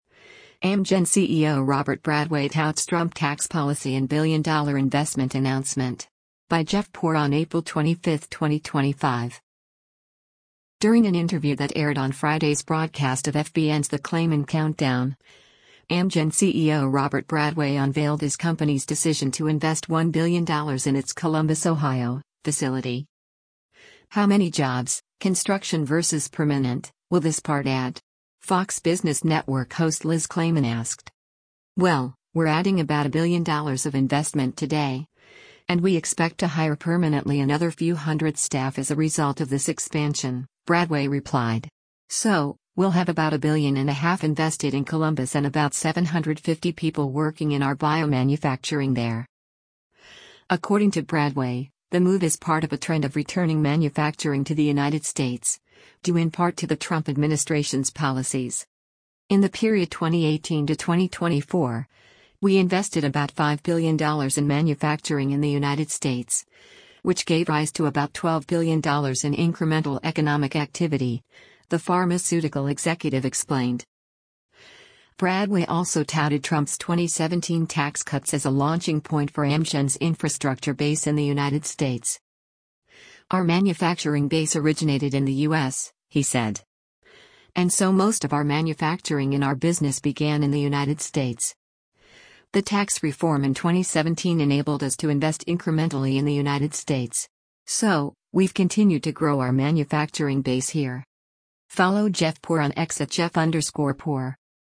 During an interview that aired on Friday’s broadcast of FBN’s “The Claman Countdown,” Amgen CEO Robert Bradway unveiled his company’s decision to invest $1 billion in its Columbus, OH, facility.